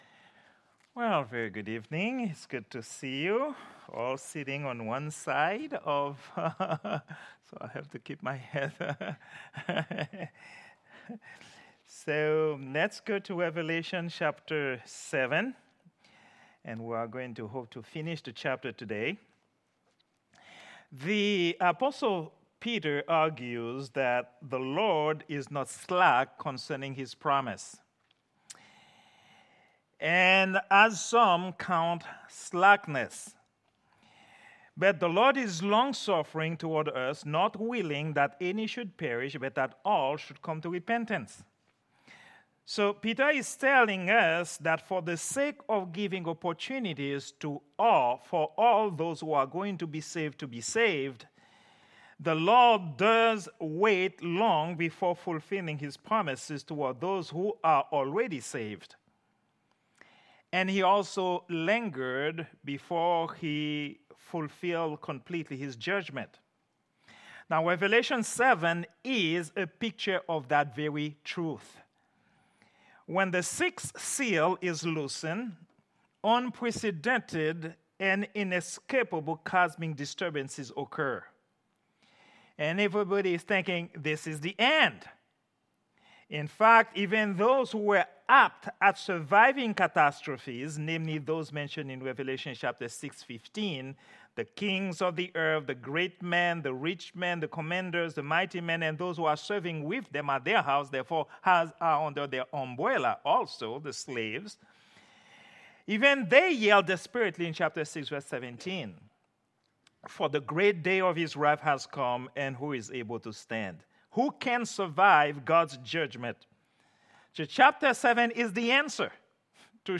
Prayer_Meeting_08_21_2024.mp3